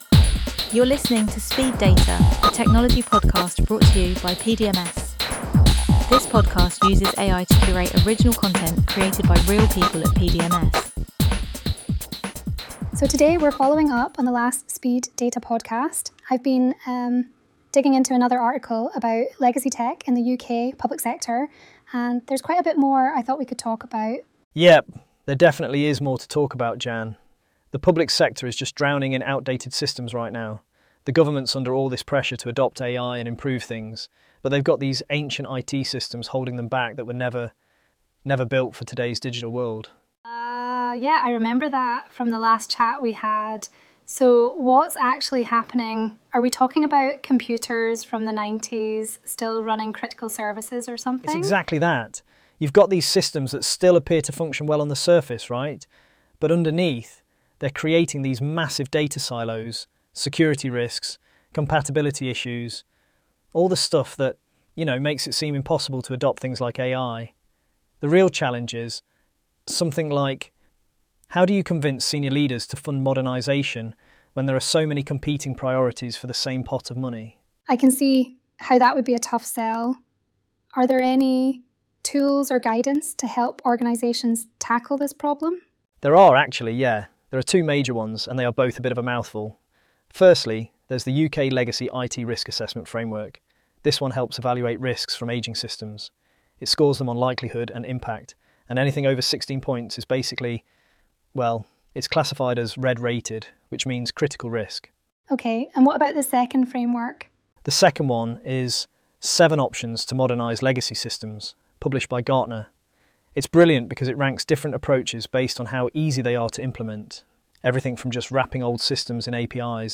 Every episode is generated using AI to help us deliver valuable content faster and more efficiently but grounded in the trusted and real-world experience of our team.